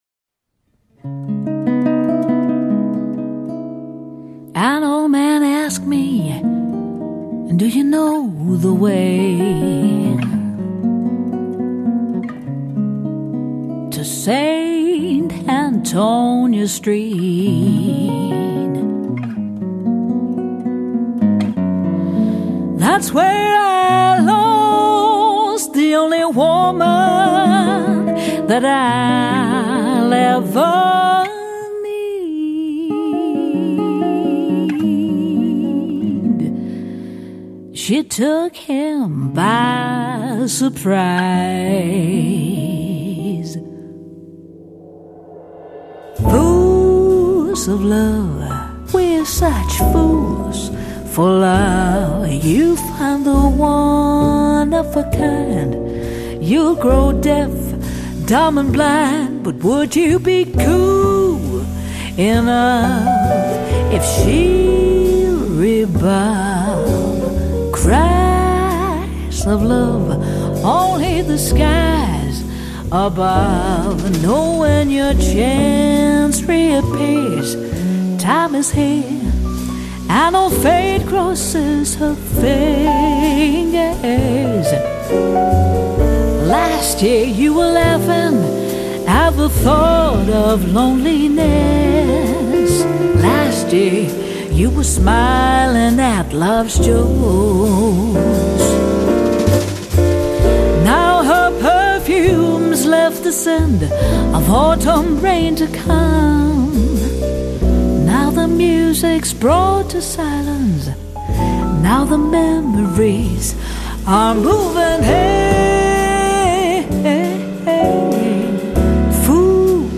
音樂類型：爵士樂
以爵士樂標準曲目〈Jazz Standards〉與酒館情歌〈Cabaret〉為主